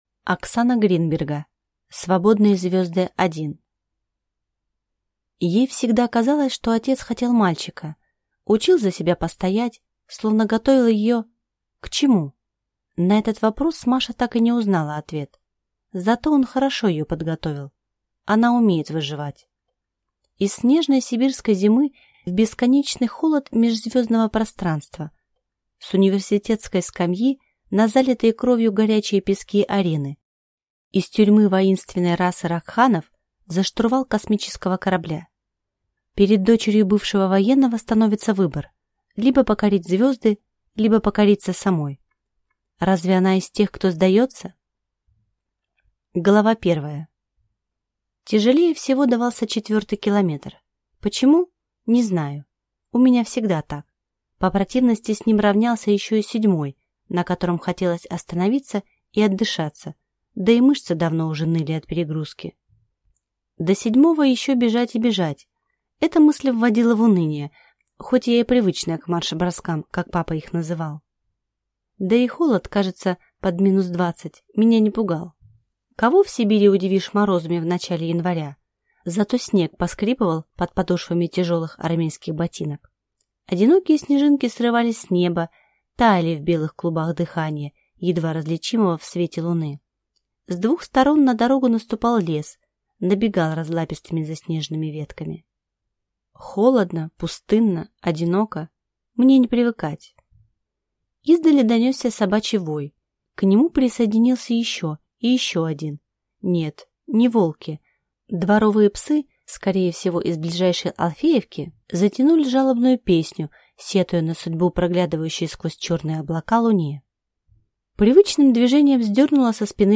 Аудиокнига Свободные Звезды 1 | Библиотека аудиокниг